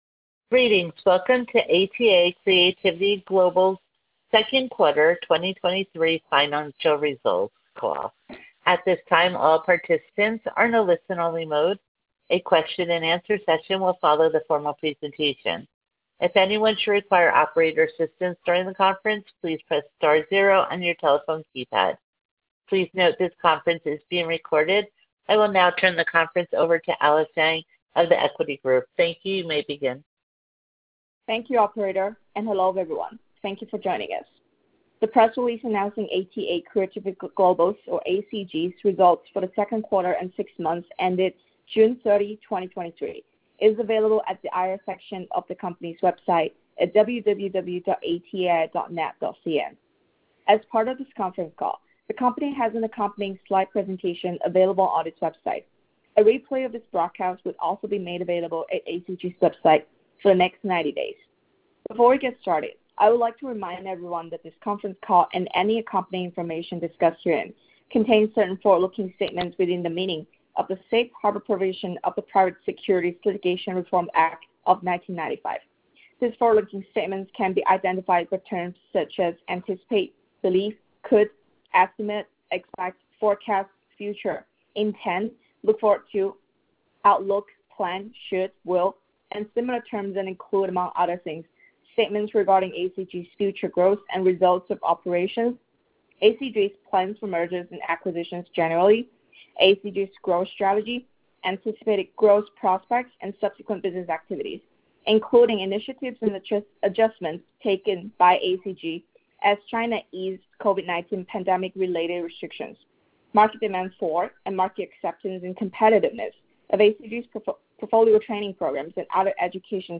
Q2 Fiscal Year 2023 Earnings Conference Call